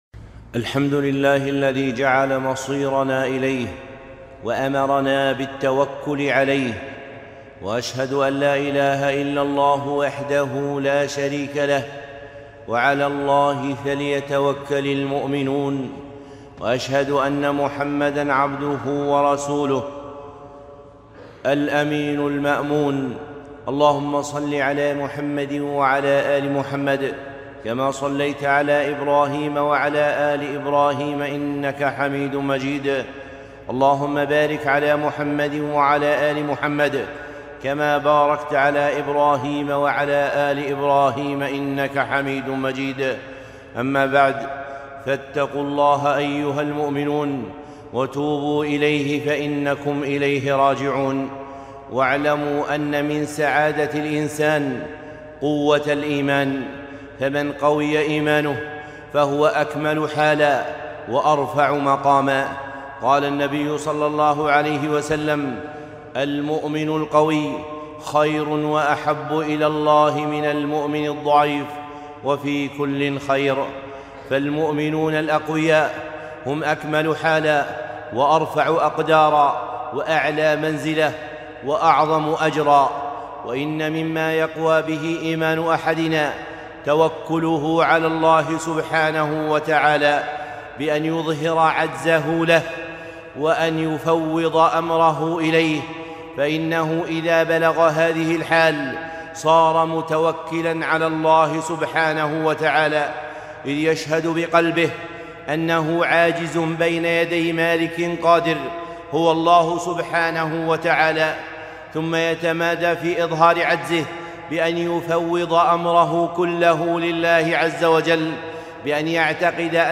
خطبة - امتحان التوكل